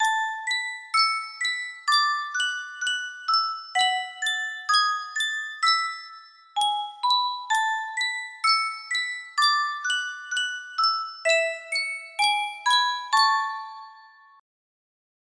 Yunsheng Music Box - Far Above Cayuga's Waters 6622 music box melody
Full range 60